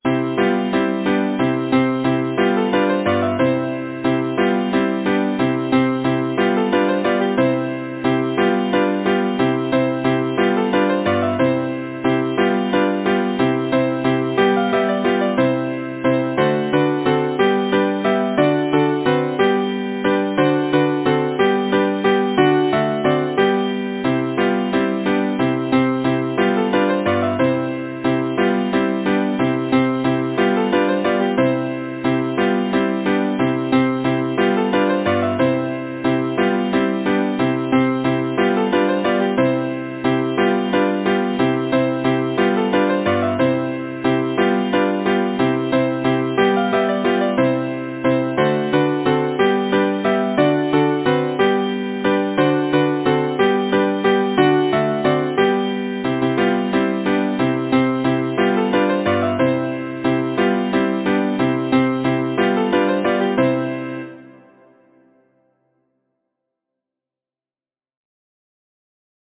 Title: What Is the Little Brook Saying? Composer: Benjamin Jepson Lyricist: Number of voices: 4vv Voicing: SATB Genre: Secular, Partsong
Language: English Instruments: A cappella